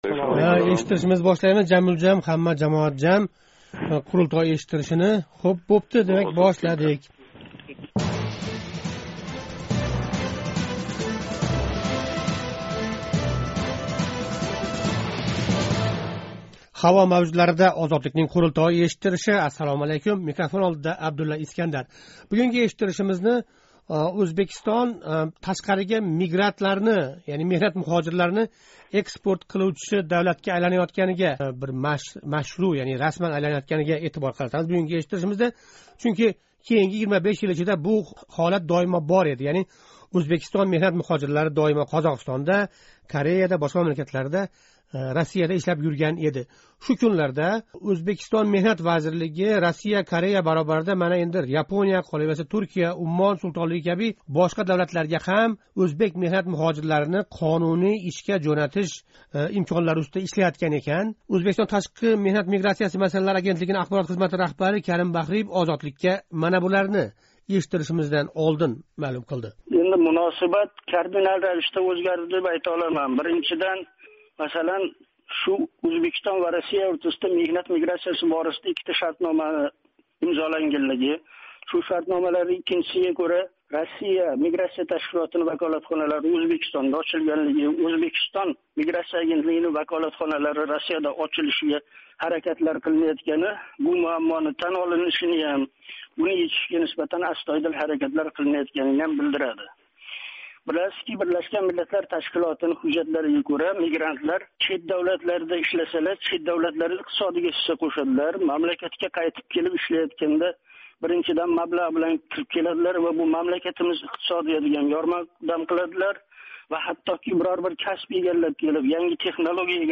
Ўзбекистон янги раҳбарияти ўз ватандошларининг меҳнат муҳожири ўлароқ ташқарига чиқишига муносабатни ўзгартирганлигини Қурултой эшиттиришида муҳокама қилдик.